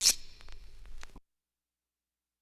Washboard.wav